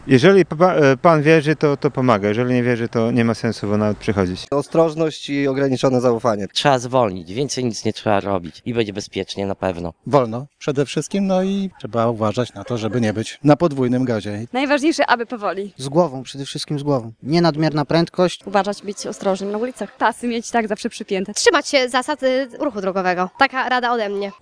Święcenie aut pomaga, jeśli się wierzy – przyznają kierowcy.
wierny.mp3